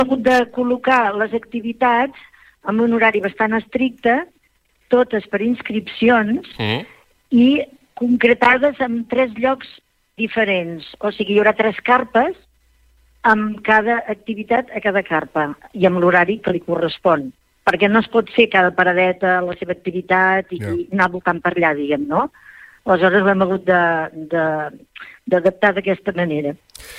Entrevistes SupermatíPalamós